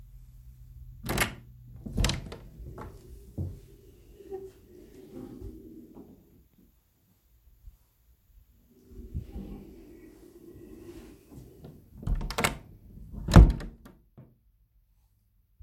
Dining-Room-door-closing.mp3